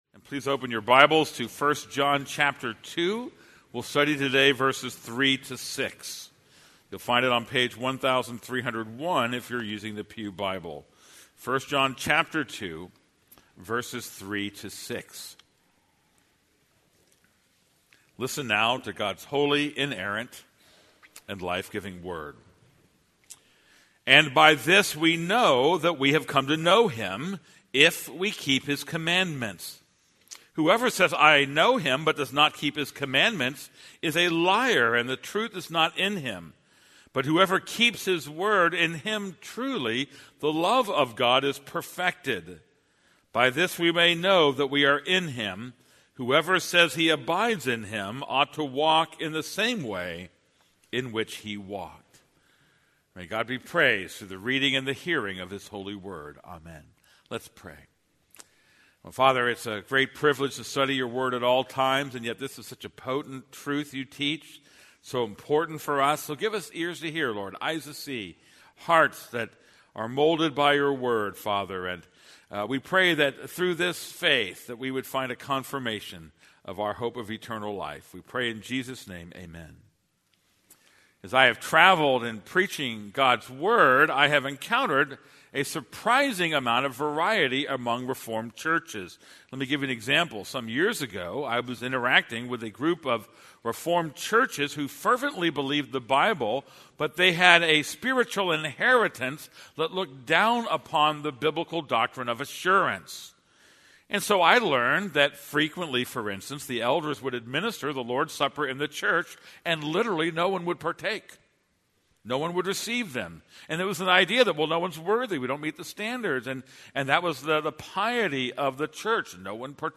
This is a sermon on 1 John 2:3-6.